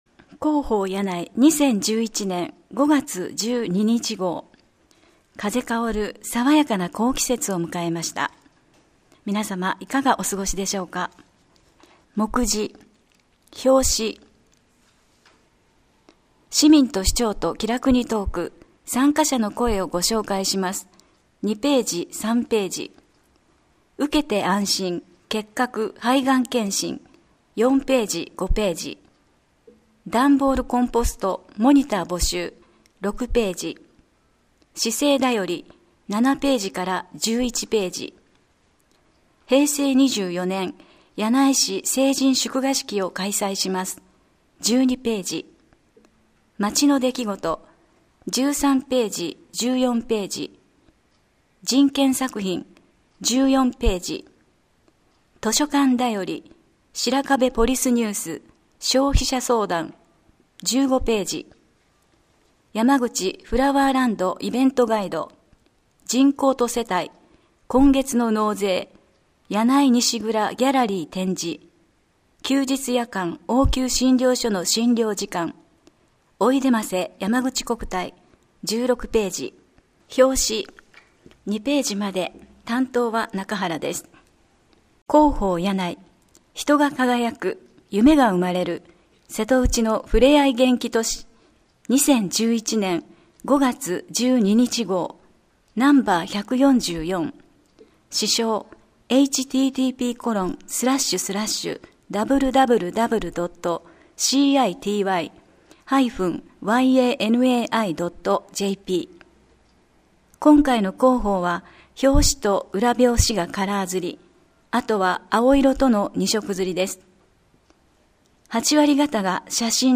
声の広報（音訳版：発行後1週間程度で利用可能）はこちらから [mp3／39.53MB]